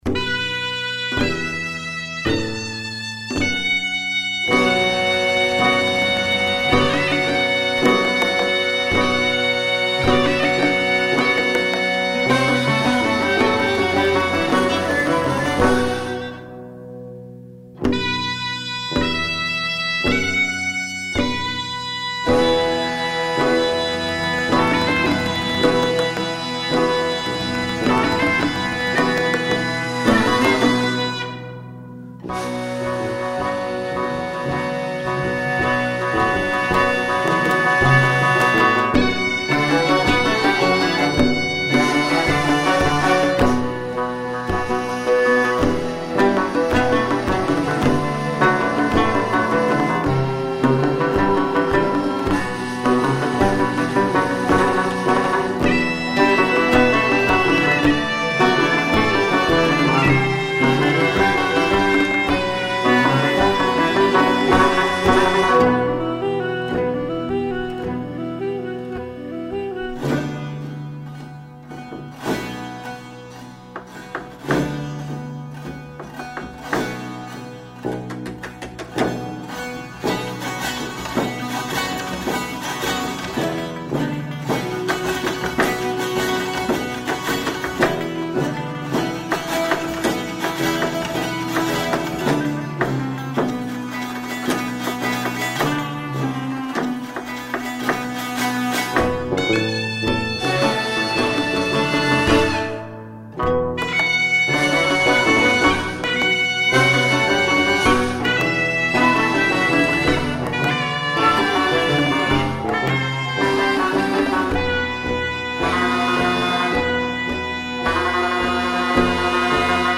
آواز